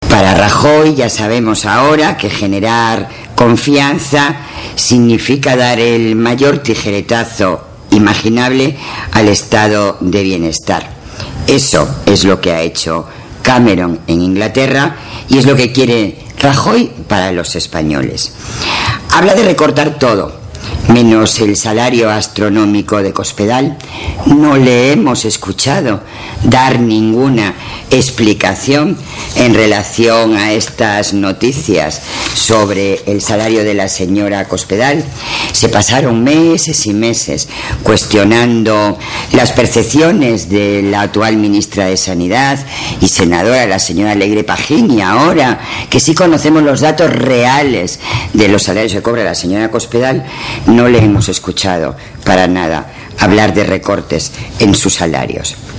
En rueda de prensa hoy en la Cámara Alta, la dirigente socialista ha asegurado que las últimas declaraciones de Rajoy acerca de su preferencia por el modelo del gobierno británico de David Cameron demuestran que el PP «está decidido a acabar con el Estado del Bienestar», a recortar derechos civiles y sociales, a exigir «trabajar más para ganar menos la mayoría, porque algunos de sus líderes trabajan poco para ganar mucho».
Cortes de audio de la rueda de prensa